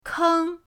keng1.mp3